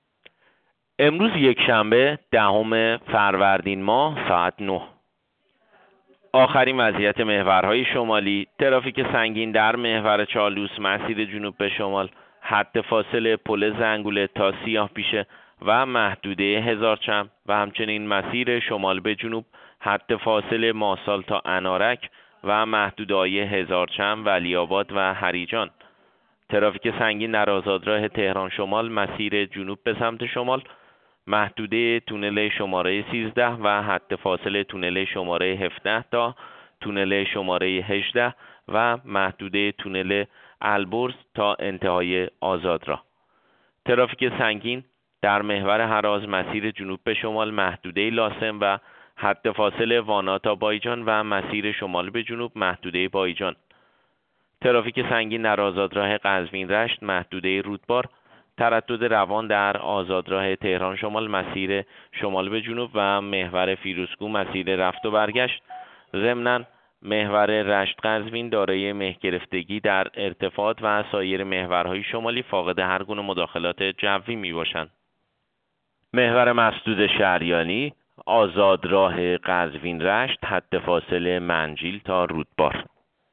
گزارش رادیو اینترنتی از آخرین وضعیت ترافیکی جاده‌ها ساعت ۹ دهم فروردین؛